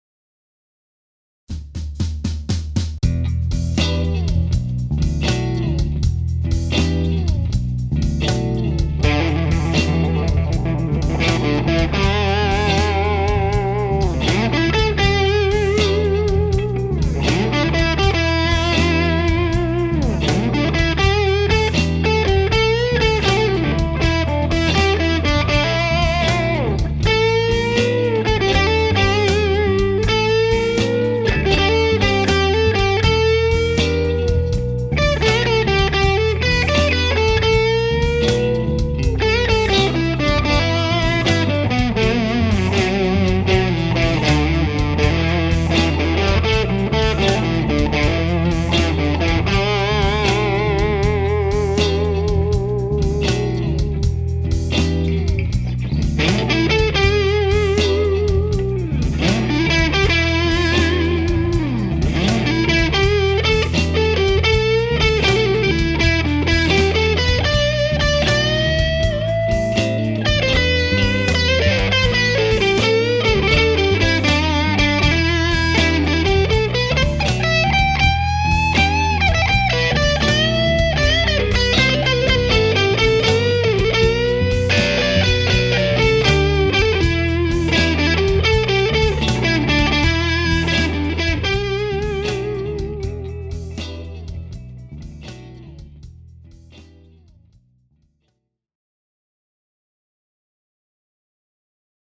I played the first part of the clip in the bridge pickup, then in the second part of the clip, I switched to the neck and activated the coil tap to get that spanky, single coil tone. But unlike a single coil, the notes continue to sustain.